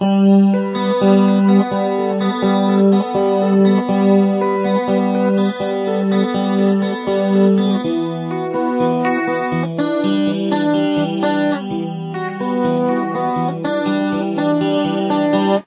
Here it is looped at normal speed (90 bpm):